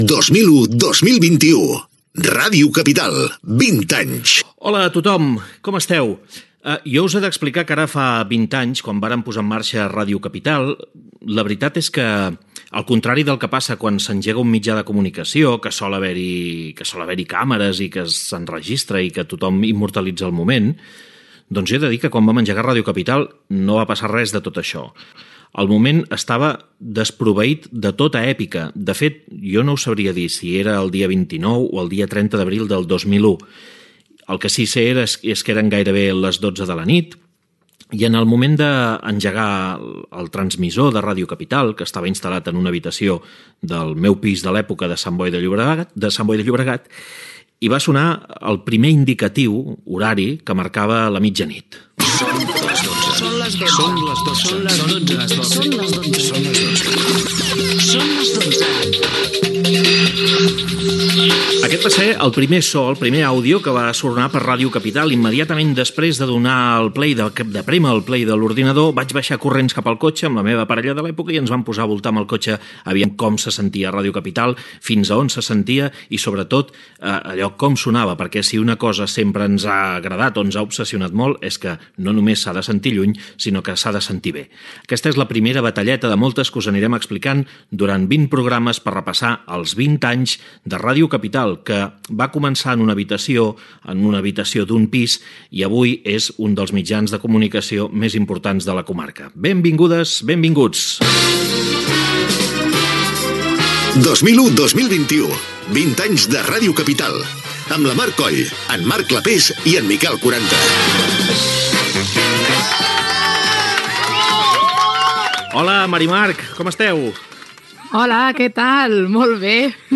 Indicatiu del programa, presentació del capítol 1, record de com va començar l'emissora l'any 2001 i diàleg amb els col·laboradors, indicatiu i presentació d'un tema musical
Divulgació